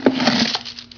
quiver_pickup.wav